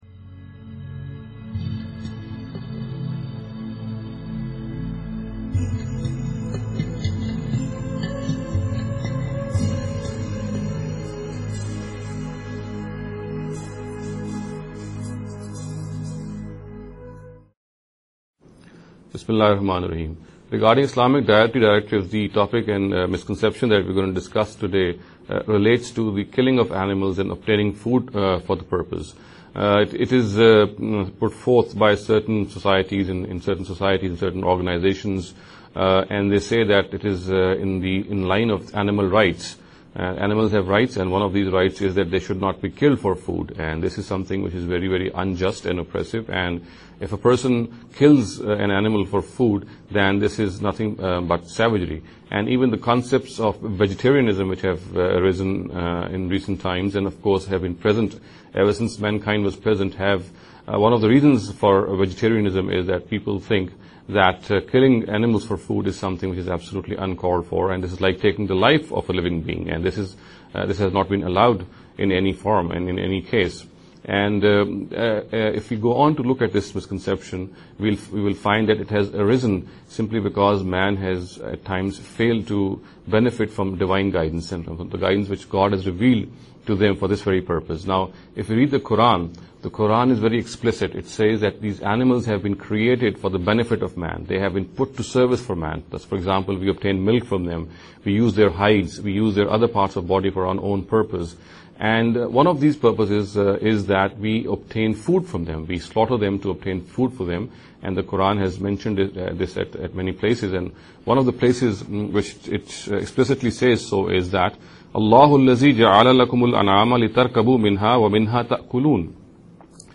This lecture series will deal with some misconception regarding The Dietary Directives of Islam.